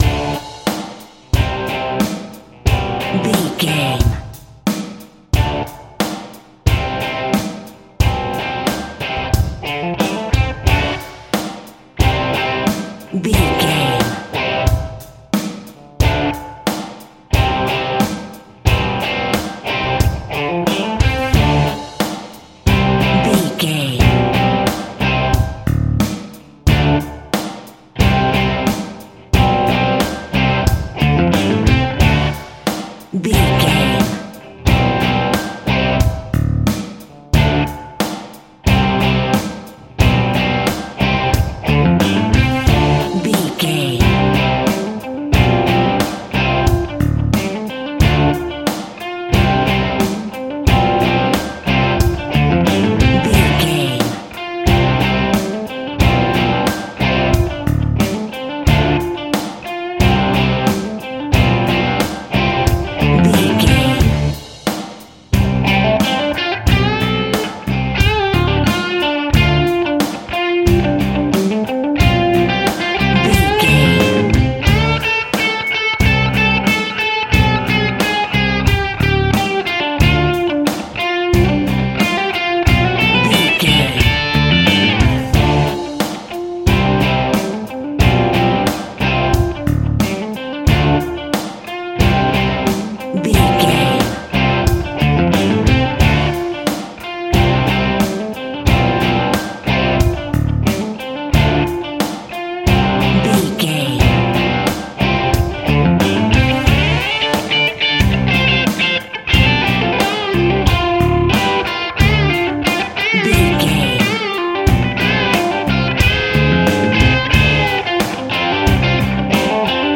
Epic / Action
Mixolydian
D